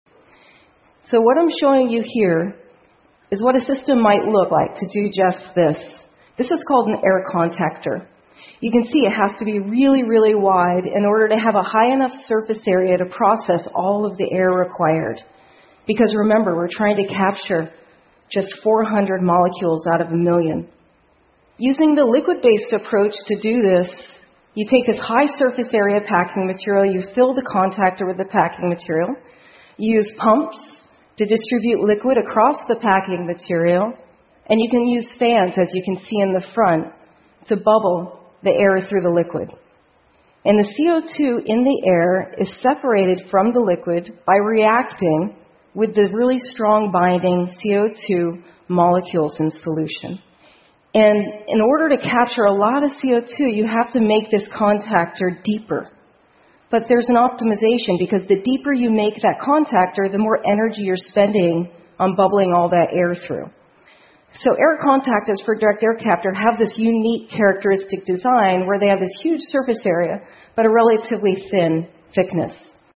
TED演讲:从大气中移除二氧化碳的新方法() 听力文件下载—在线英语听力室